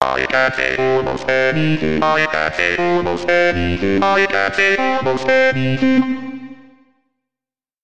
Hier sind Demo-Videos und Soundbeispiele zu den einzelnen Outstanding Synths: